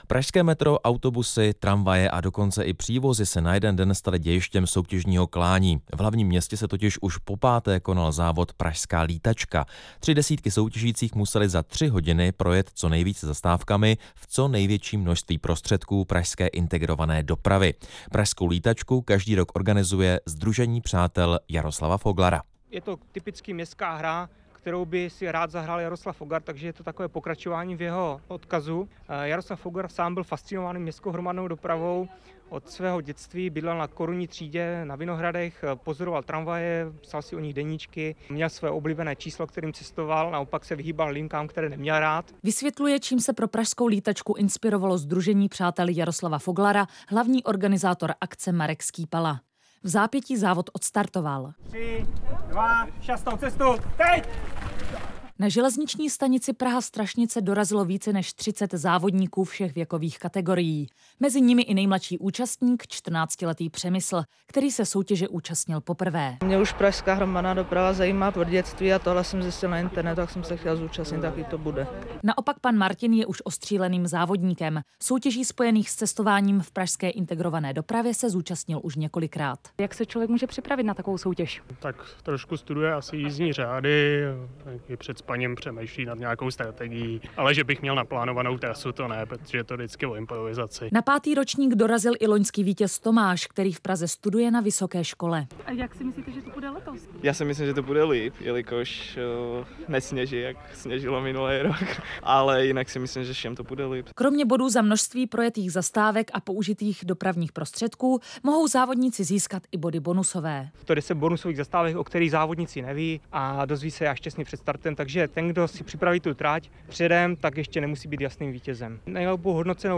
Rozhlasové zprávy
rozhlas - Pražská lítačka